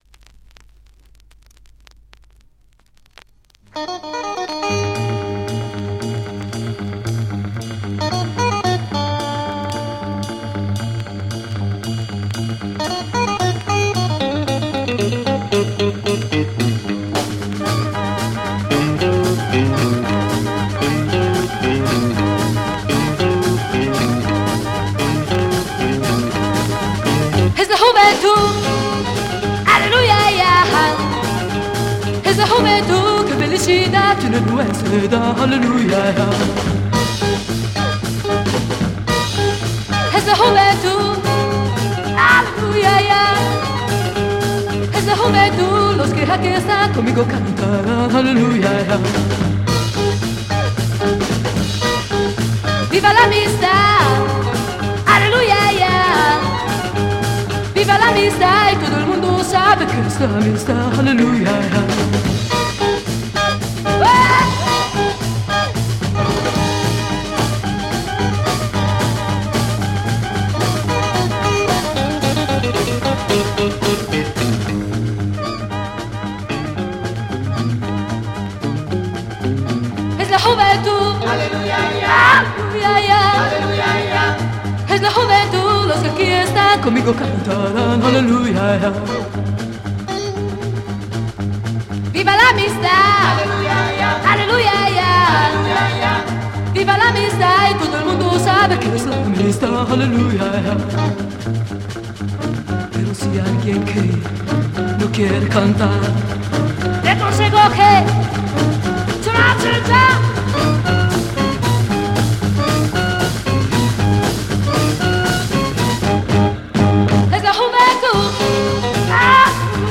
Italian girl Freakbeat Garage Surf EP
Canta en Espanol :